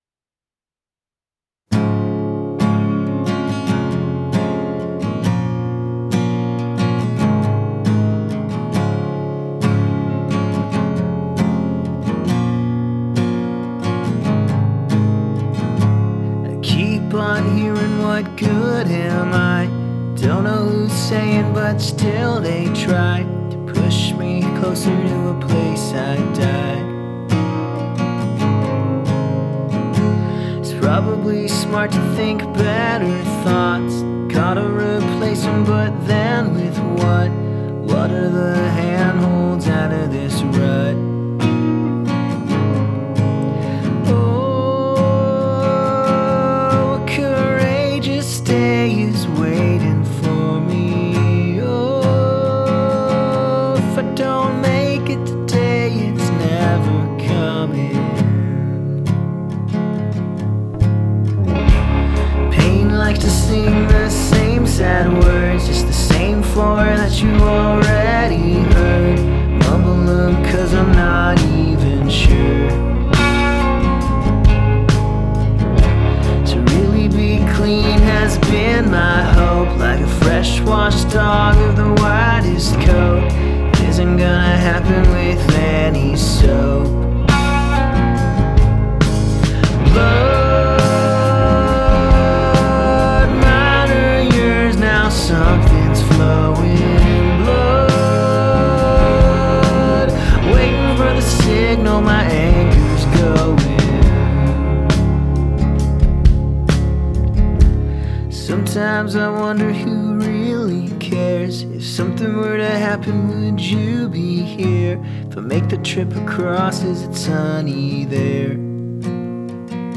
PHAT and crisp folk rock
Hi friends, I've been working on making this acoustic folk rock tune equally PHAT and crisp.